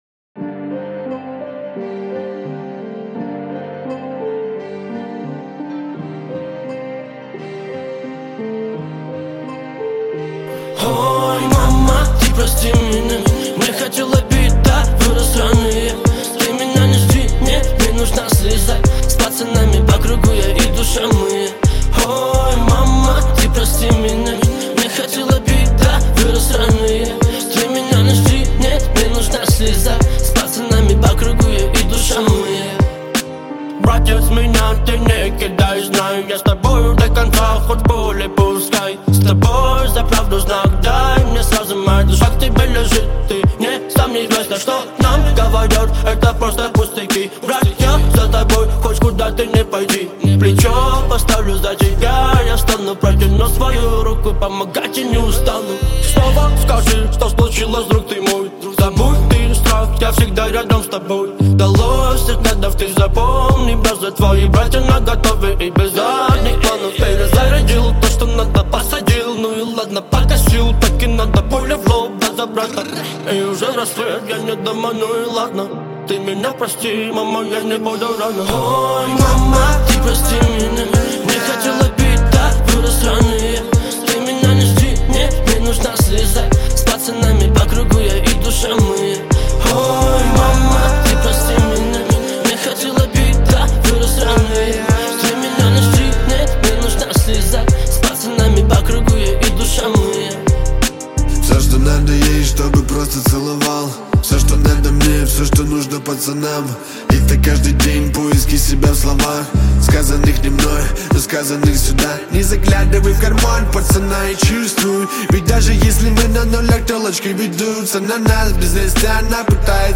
Русский поп